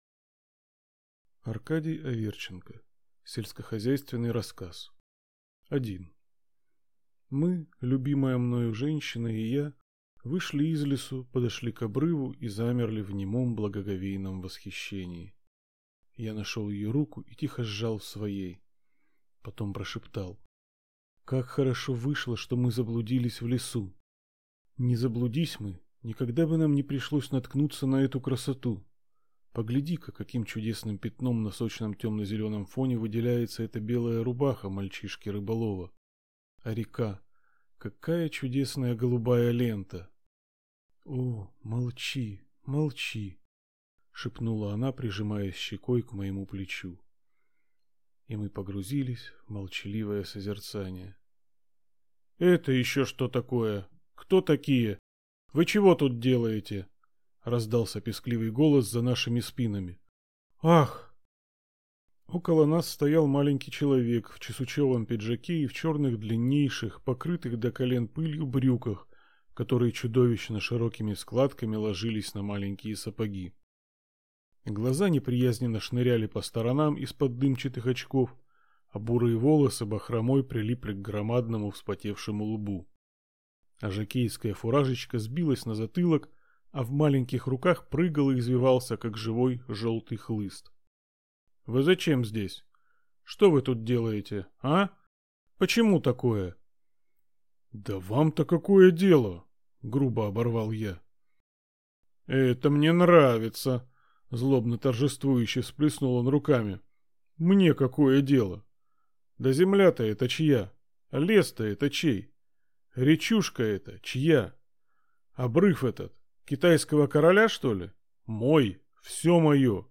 Аудиокнига Сельскохозяйственный рассказ | Библиотека аудиокниг